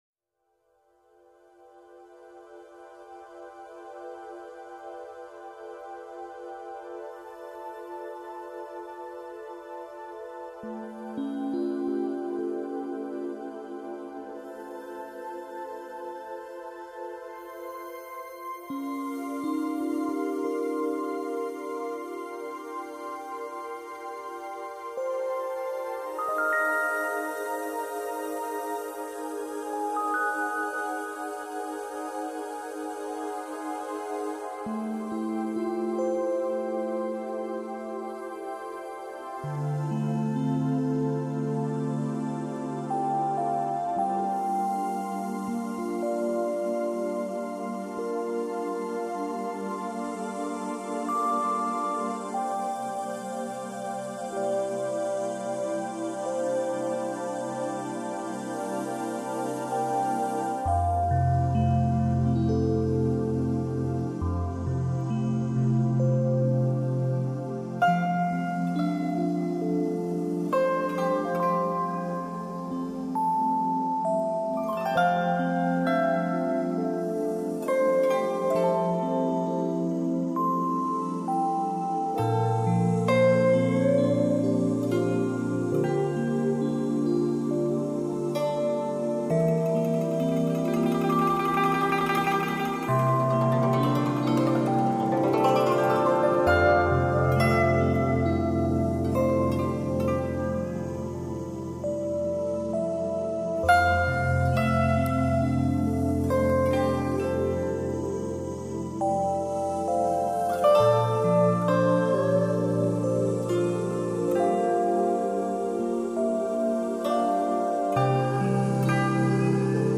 大自然的音乐，轻松乐曲